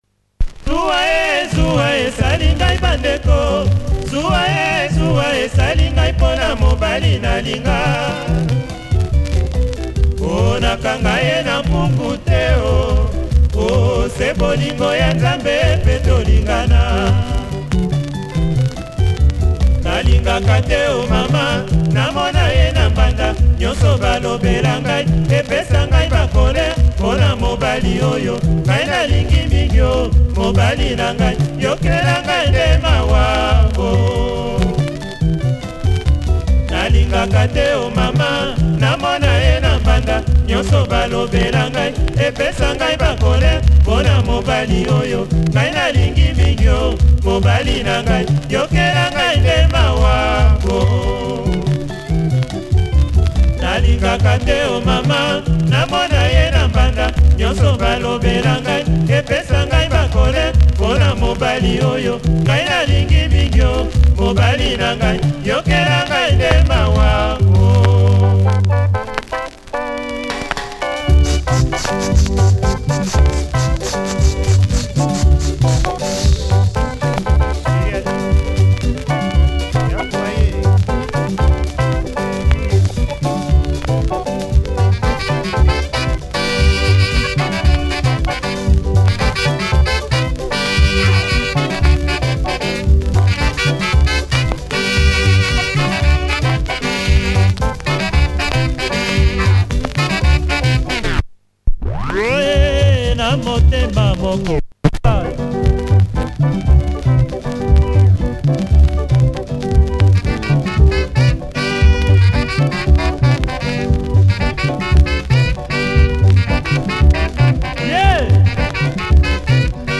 Nice 80s track